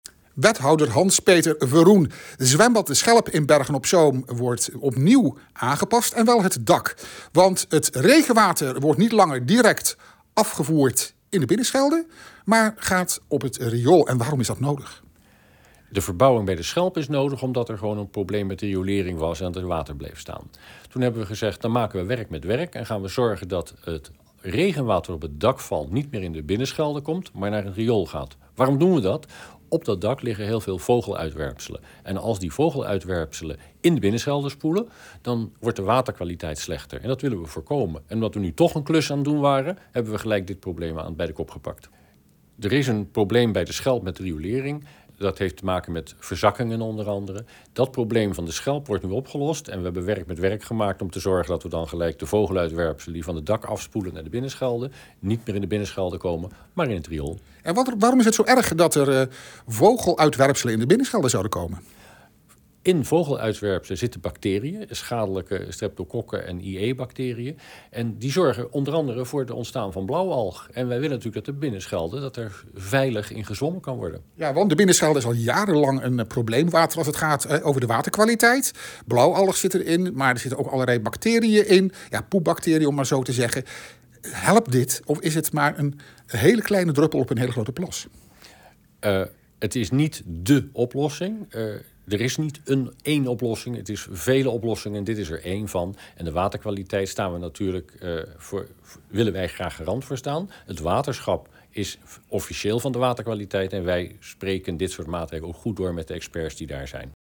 Audio: Wethouder Hans Peter Verroen legt uit waarom de rioolaanpassing nodig is.